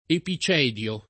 vai all'elenco alfabetico delle voci ingrandisci il carattere 100% rimpicciolisci il carattere stampa invia tramite posta elettronica codividi su Facebook epicedio [ epi ©$ d L o ] s. m. (stor.); pl. ‑di (raro, alla lat., -dii )